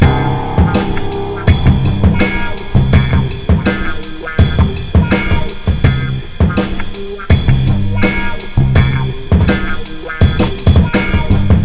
building steam.wav